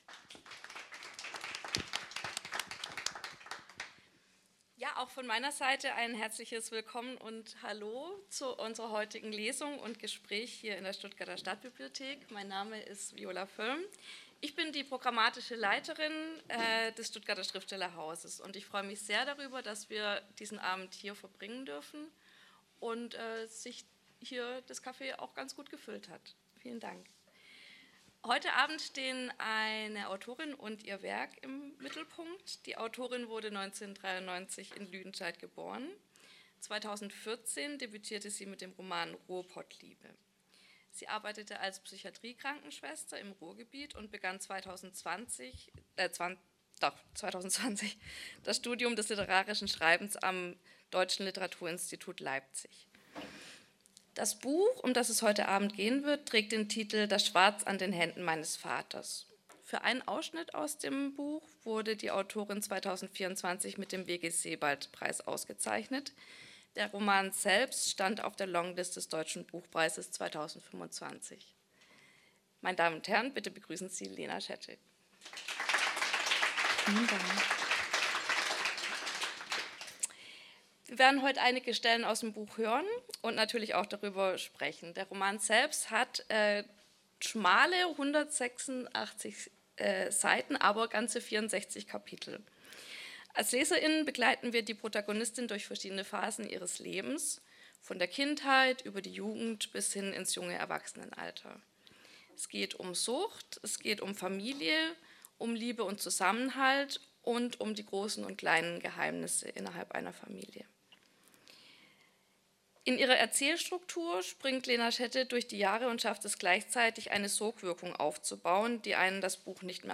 Lesung
Gespräch und Lesung